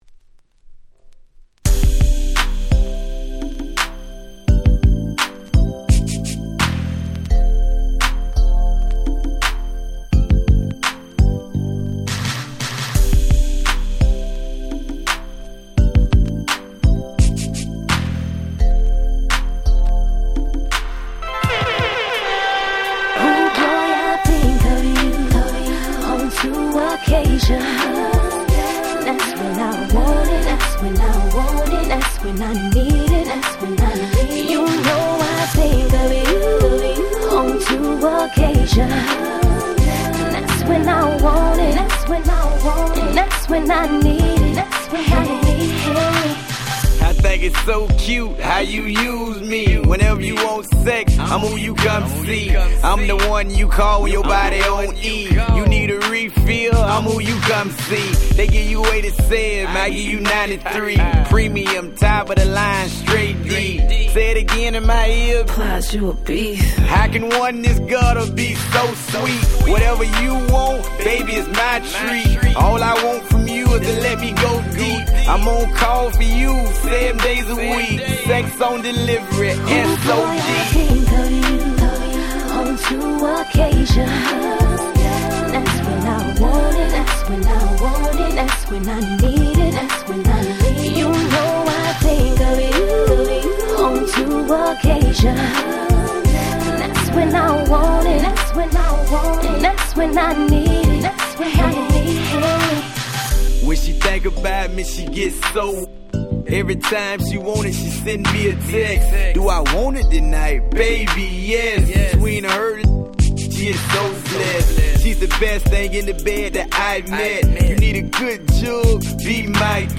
Hip Hop R&B